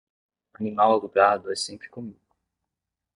Read more Meaning past participle of comer 🍽 comer Verb Noun Read more Frequency C1 Pronounced as (IPA) /koˈmi.du/ Bookmark this Improve your pronunciation Notes Sign in to write sticky notes